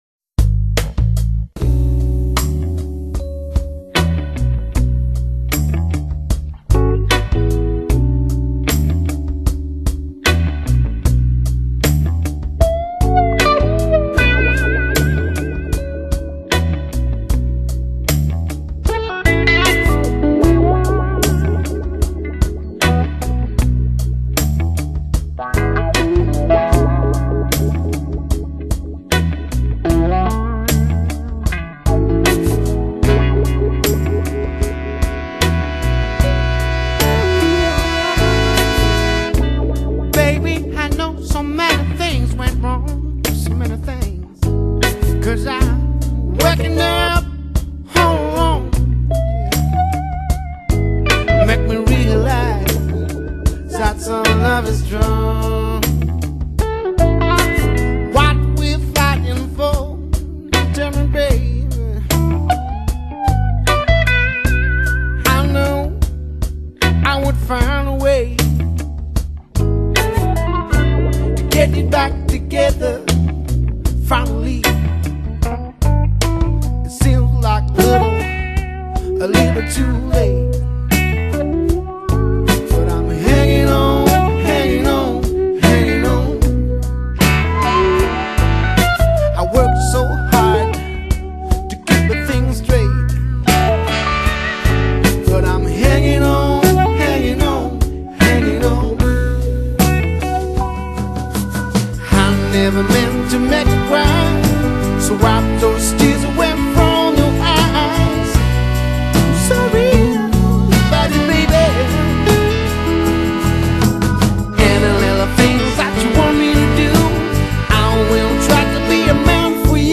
音樂風格︰Blues | 1CD |